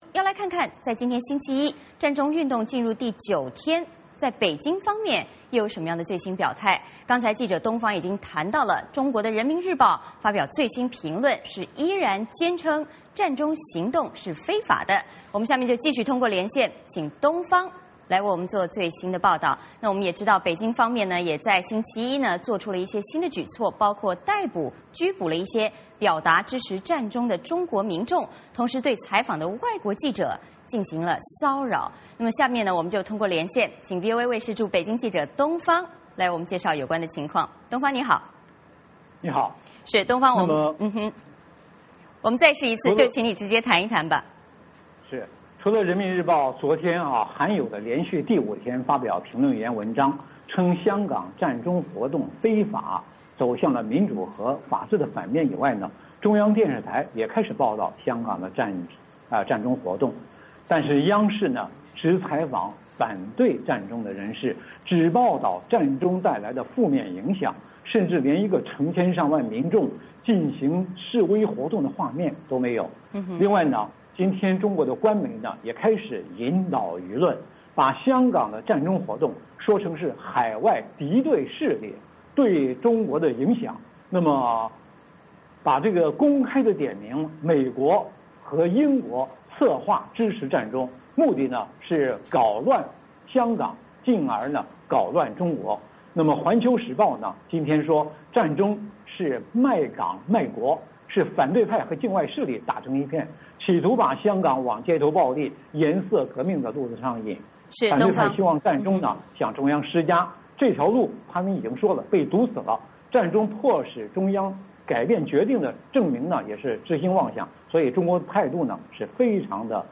VOA连线：北京逮捕支持占中民众，干扰记者采访